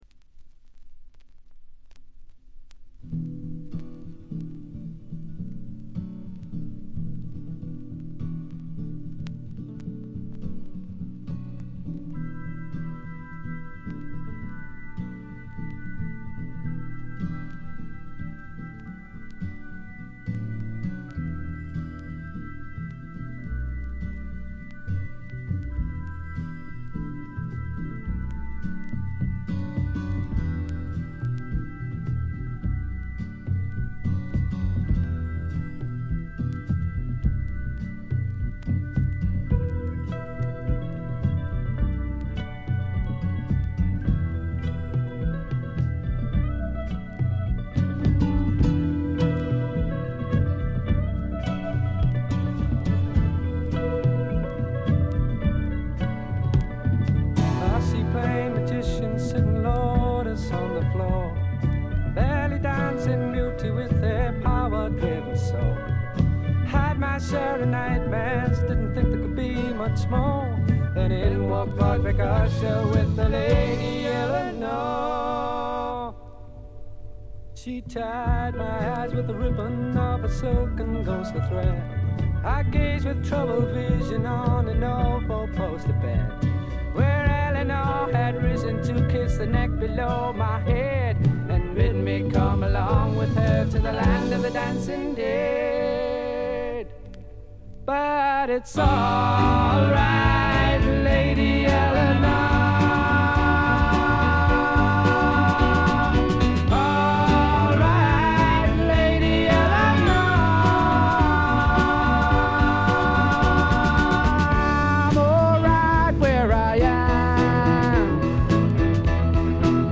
わずかなノイズ感のみ。
英国フォークロック基本！
試聴曲は現品からの取り込み音源です。